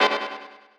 snd_tensionhorn_ch1.wav